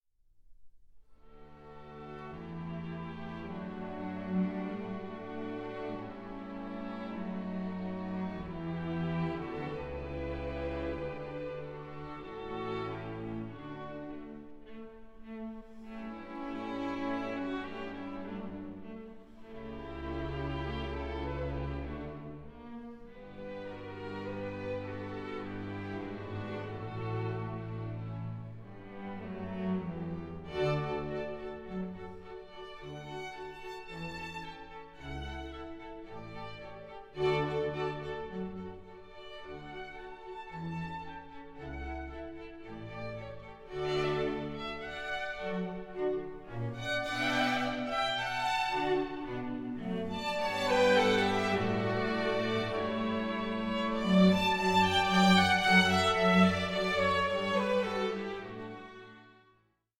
Andante 8:05